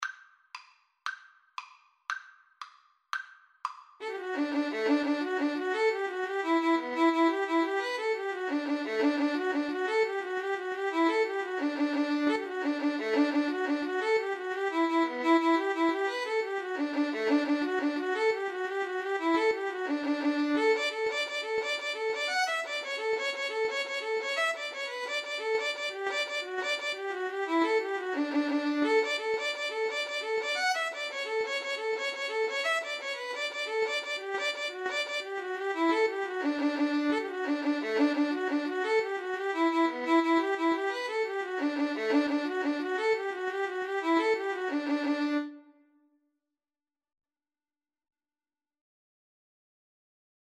Free Sheet music for Violin-Cello Duet
D major (Sounding Pitch) (View more D major Music for Violin-Cello Duet )
6/8 (View more 6/8 Music)
Allegro .=c.116 (View more music marked Allegro)
Traditional (View more Traditional Violin-Cello Duet Music)